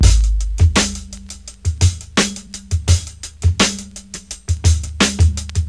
• 84 Bpm High Quality Hip-Hop Breakbeat Sample G# Key.wav
Free drum loop - kick tuned to the G# note. Loudest frequency: 1559Hz
84-bpm-high-quality-hip-hop-breakbeat-sample-g-sharp-key-uTh.wav